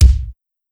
Tuned drums (C key) Free sound effects and audio clips
• Old School Wet Kick Single Hit C Key 364.wav
Royality free kick drum single hit tuned to the C note. Loudest frequency: 497Hz
old-school-wet-kick-single-hit-c-key-364-E3E.wav